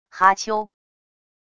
哈秋~wav音频